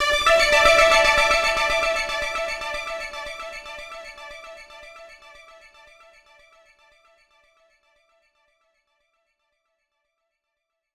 Echoes_Dmaj.wav